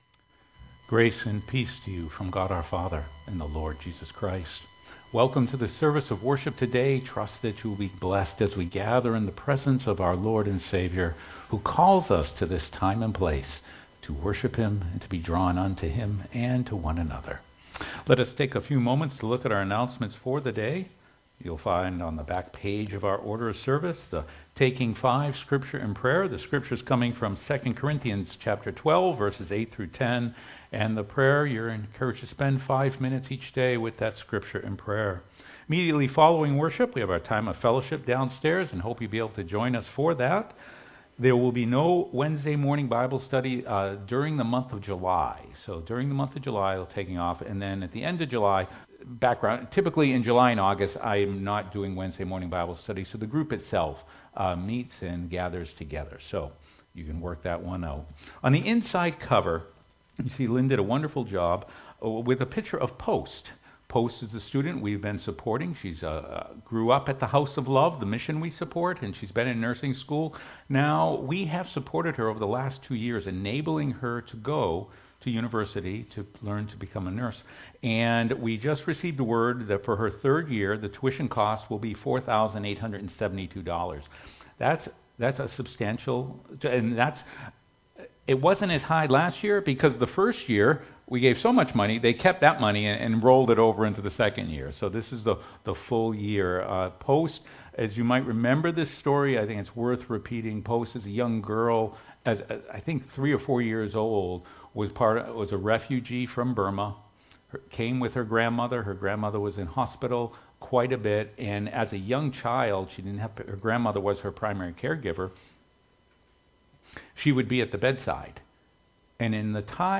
sermon-2.mp3